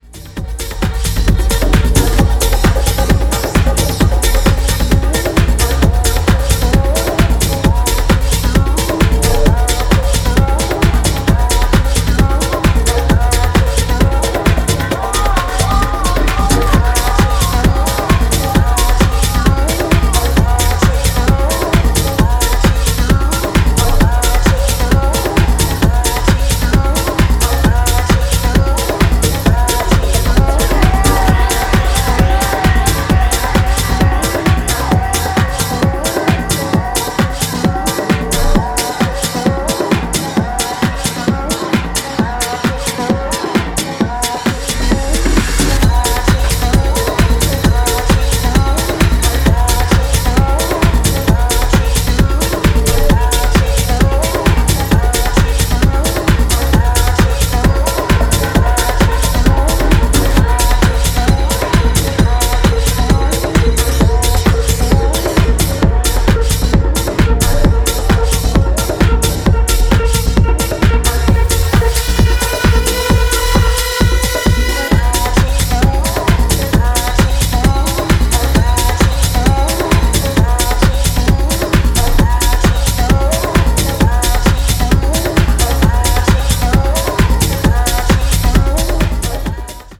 House Bass Breaks